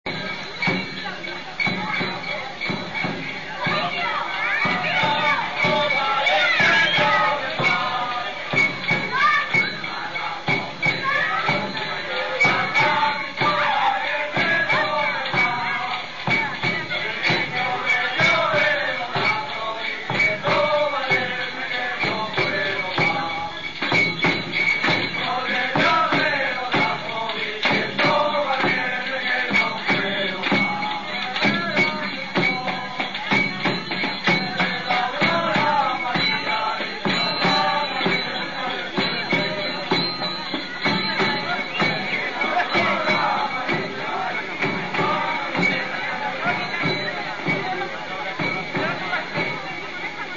El "tan tán tan tararán tan" de la tambora, acompasado, sonoro y enérgico les llega al alma a los cambileños.
Durante toda la noche del 7 al 8, la tambora recorre las calles del pueblo y, en el silencio del invierno, se oyen los cantos -un tanto quejumbrosos- acercándose poco a poco, pasando y retirándose luego hasta desvanecerse.
Hay muchas estrofas, el poeta no escatimó, pero la gente repite sólo unas pocas y éstas cantadas con desorden y un poco atropelladamente.
También algunos echan sus traguillos, los que van toda la noche acompañando con sus instrumentos de cuerda.